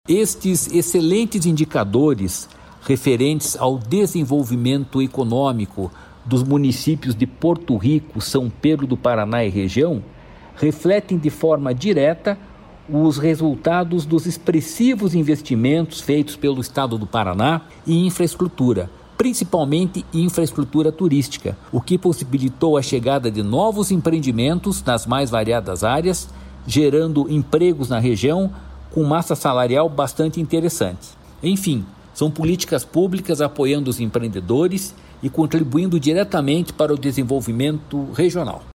Sonora do presidente do Ipardes, Jorge Callado, sobre o crescimento no número de empresas e ICMS em Porto Rico e São Pedro do Paraná